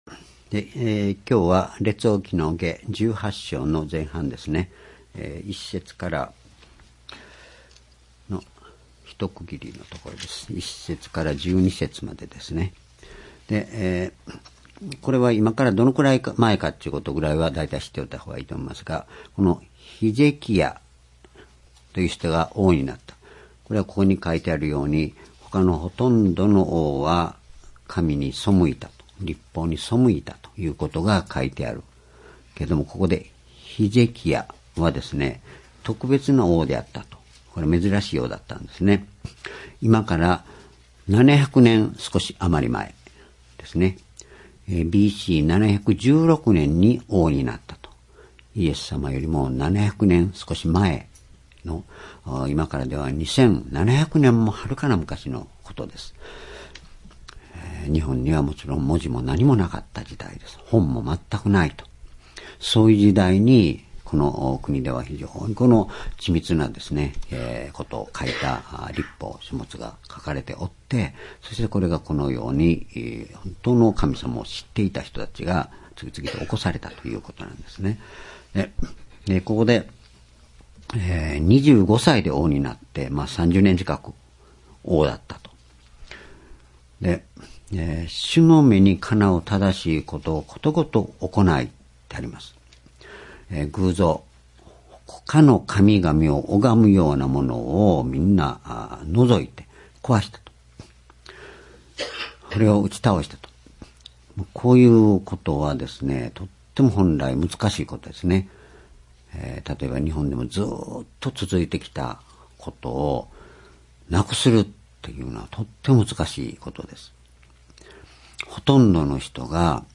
（主日・夕拝）礼拝日時 2020年2月2日 主日 聖書講話箇所 「主が共にいれば、すべては良きに」 列王記下18章1節～12節 ※視聴できない場合は をクリックしてください。